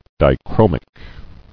[di·chro·mic]